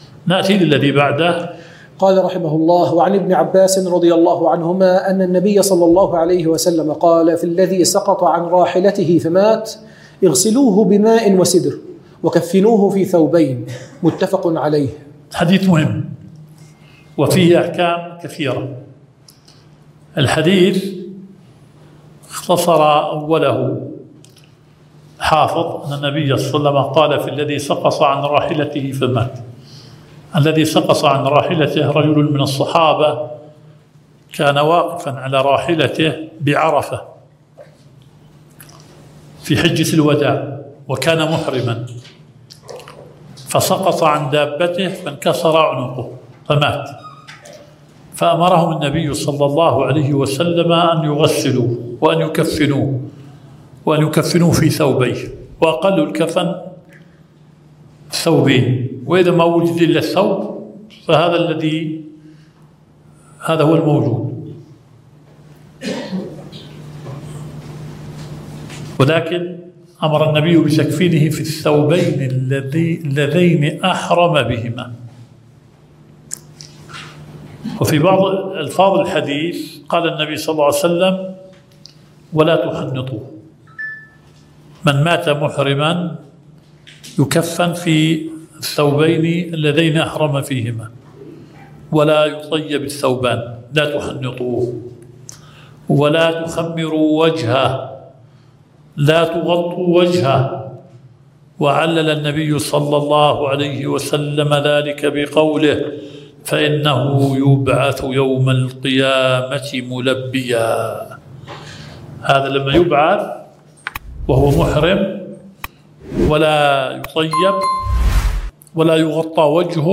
البث المباشر – المحاضرة الثانية 🔸شرح بلوغ المرام (باب صلاة العيدين – آخر كتاب الزكاة)